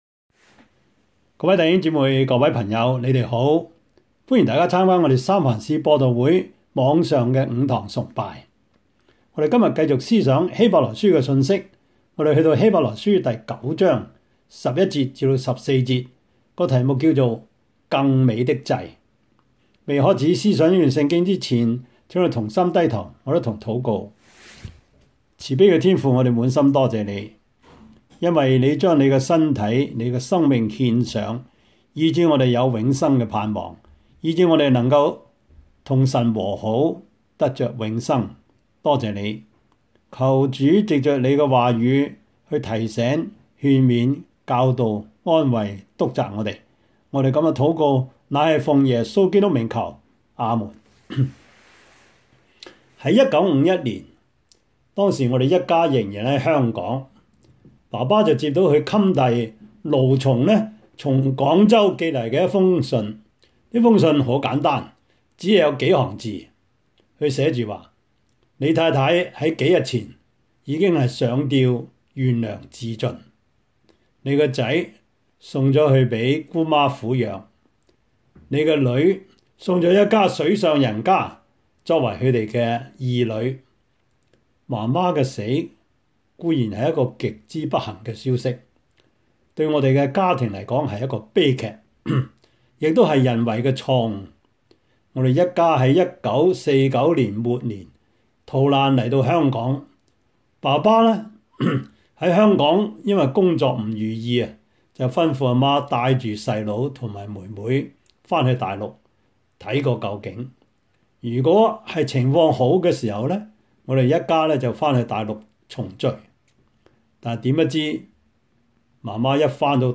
Service Type: 主日崇拜
Topics: 主日證道 « 第十二課: 太平天國的國民教育 稱謝讚美主 »